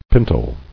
[pin·tle]